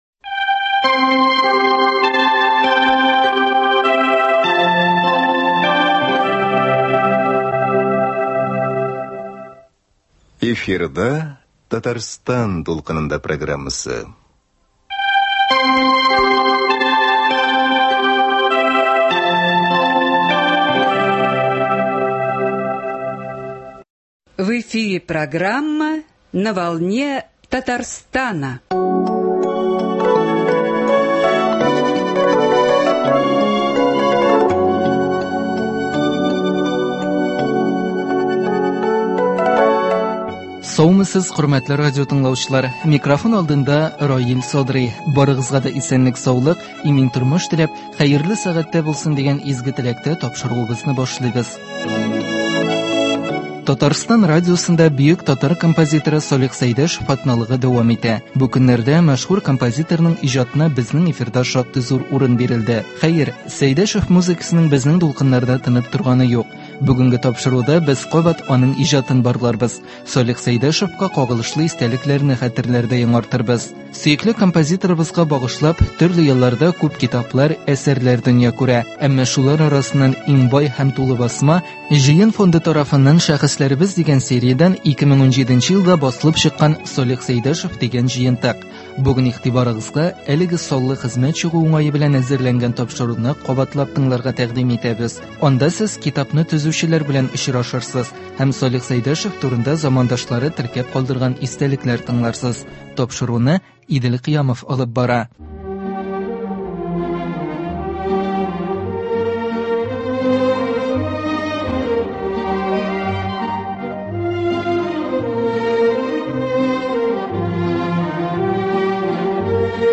Анда сез китапны төзүчеләр белән очрашырсыз һәм Салих Сәйдәшев турында замандашлары теркәп калдырган истәлекләр тыңларсыз.